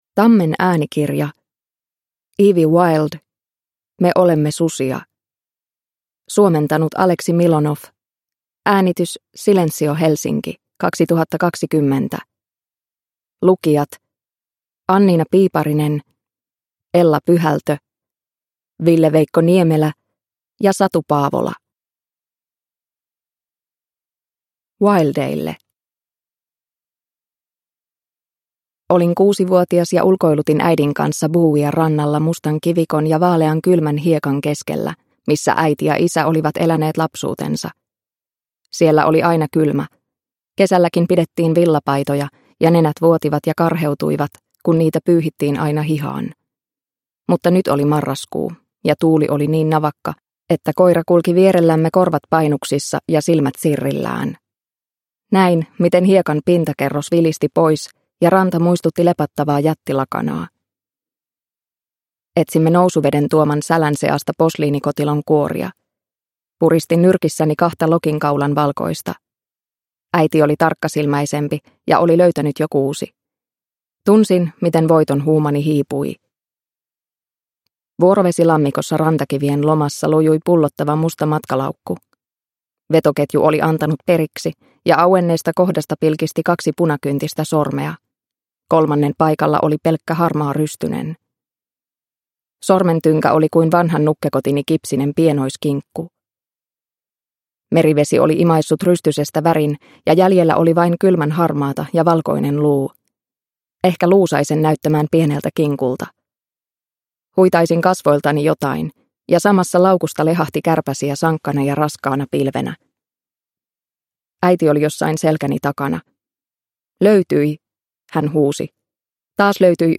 Me olemme susia – Ljudbok – Laddas ner